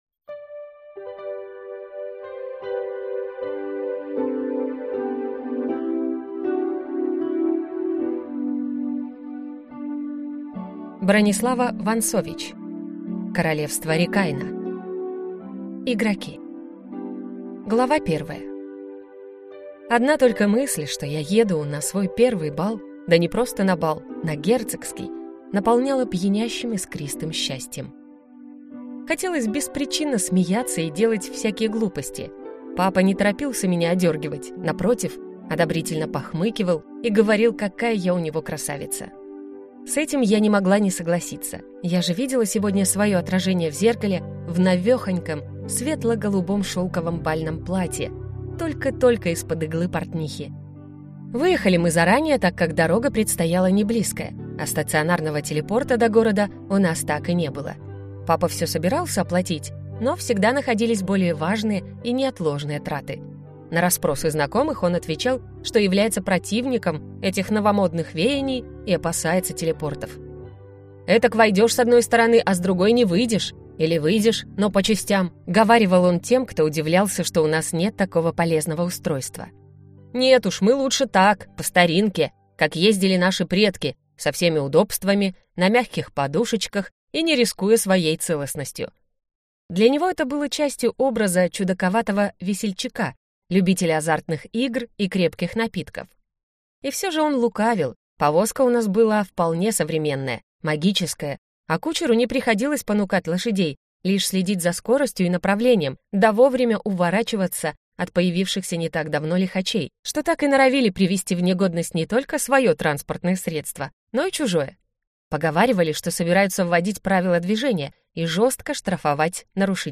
Аудиокнига Игроки - купить, скачать и слушать онлайн | КнигоПоиск